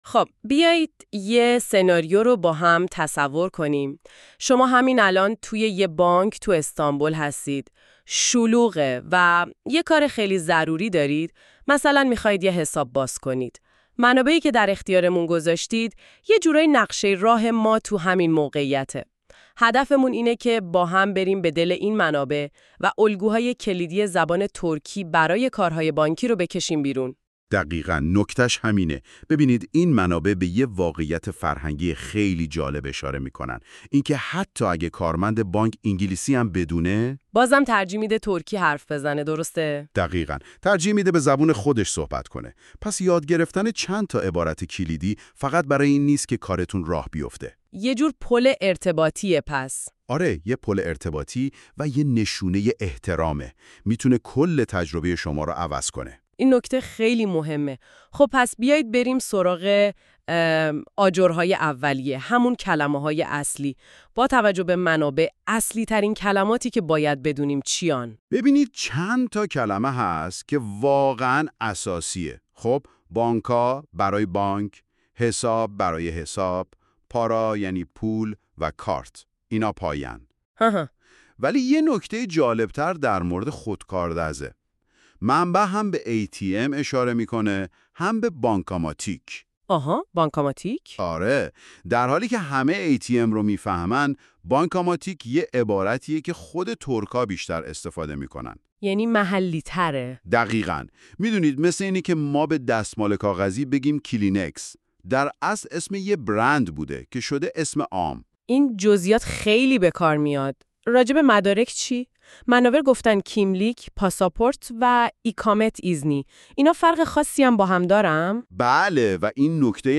bank-turkish-conversation.mp3